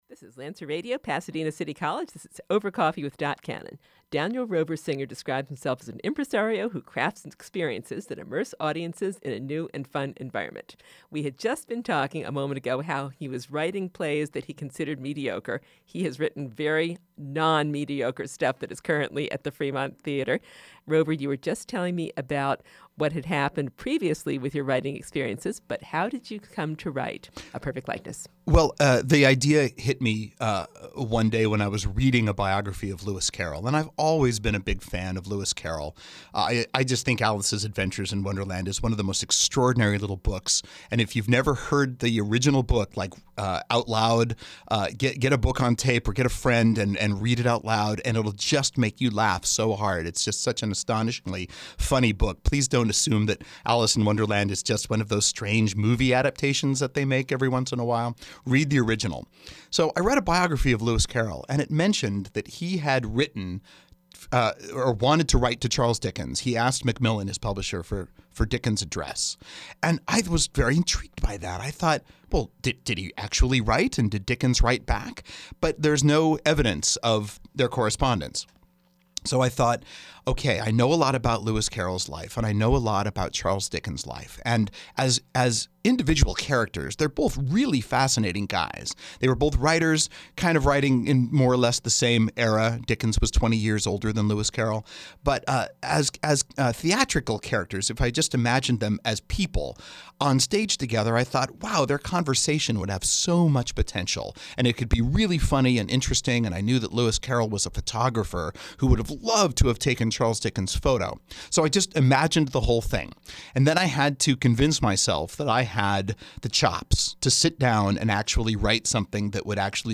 Interview, Part Two